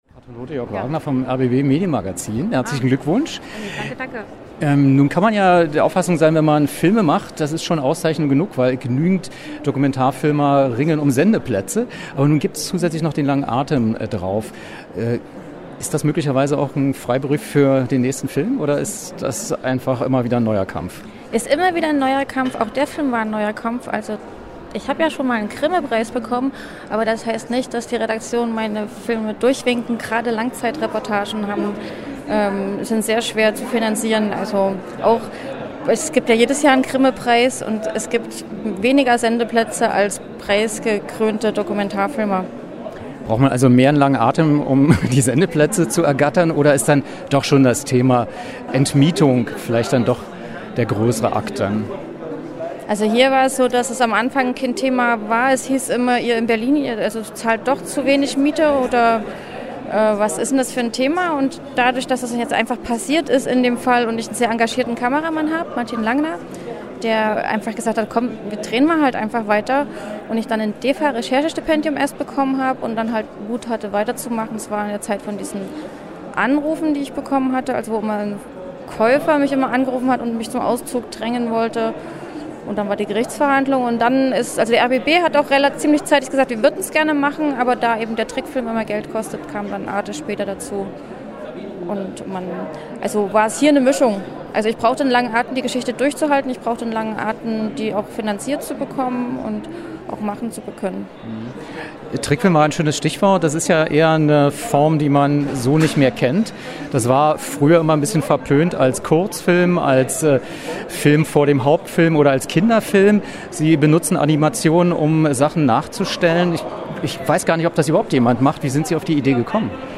Was: Interview zum 3.Preis
Wo: Berlin, Akademie der Künste, Pariser Platz